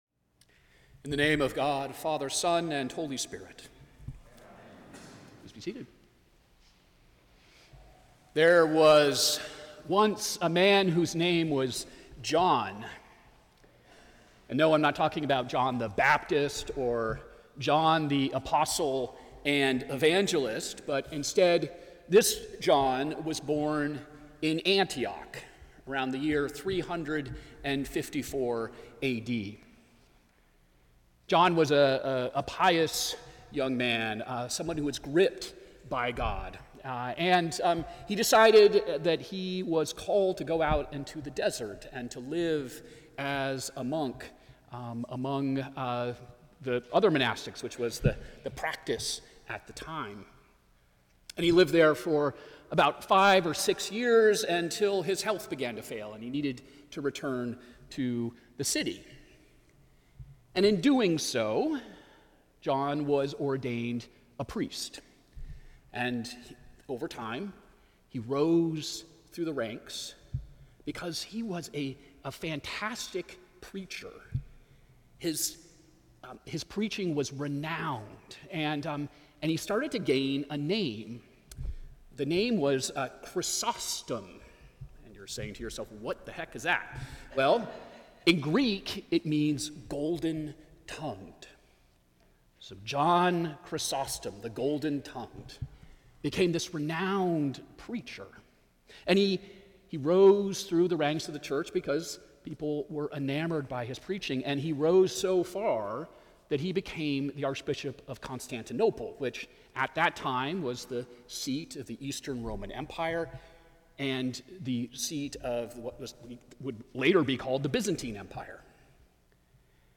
St. Augustine by-the-Sea Sermons